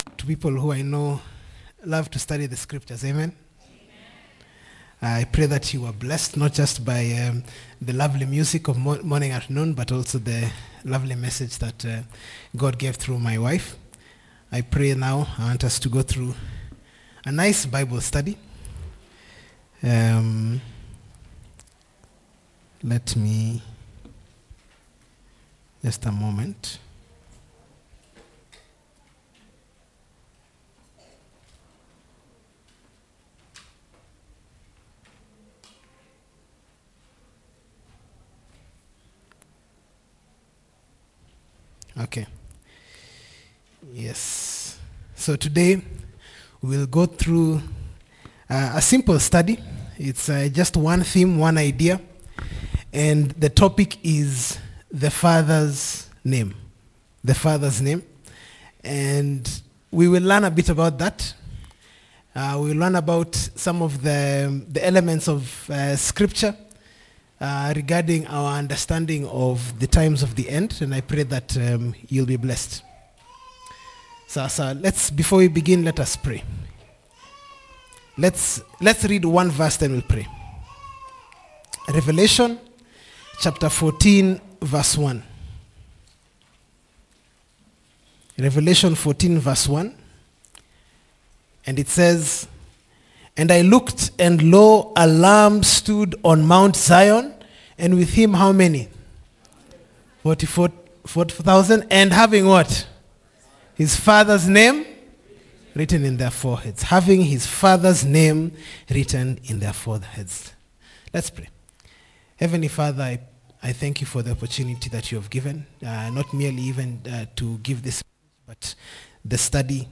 29719-Sabbath_tuksda_afternoon.mp3